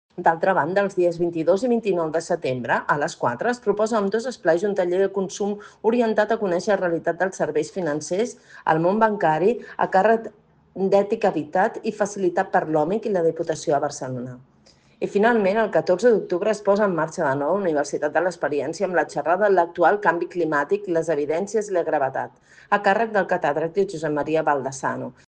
Montserrat Salas, regidora de Gent Gran de l'Ajuntament